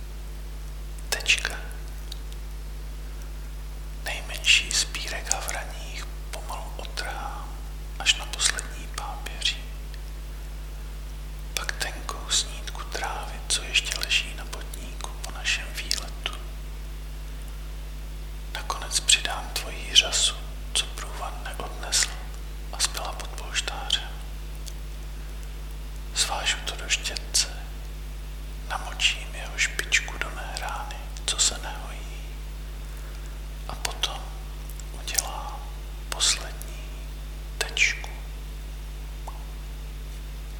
Básně » Ostatní
Působivá tečka, z toho šeptání mě příjemně mrazí.
ten závěrečný zvuk